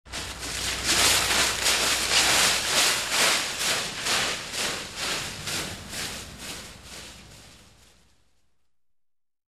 Звуки шагов в лесу
• Качество: высокое
Скачивайте реалистичные записи шагов по мягкой траве, хрустящему осеннему ковру из листьев и утоптанным земляным тропам.